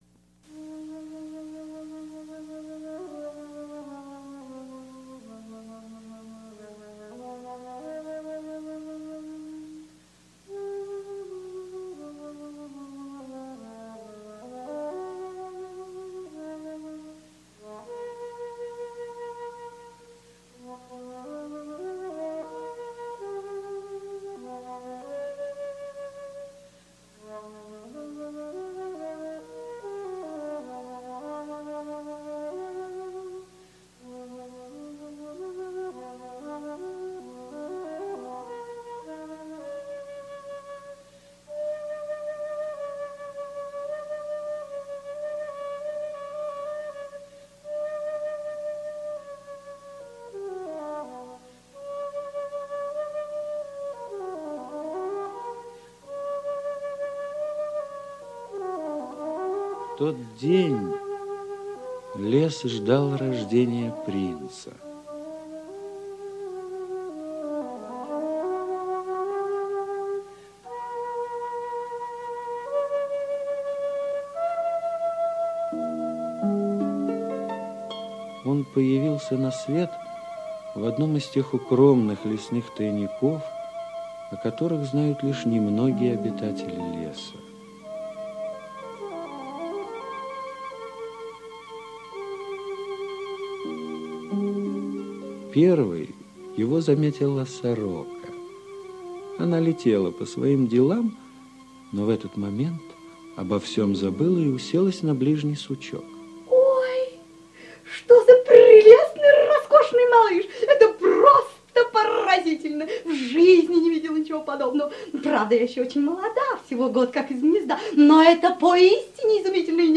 Бэмби - аудиосказка Зальтена - слушать онлайн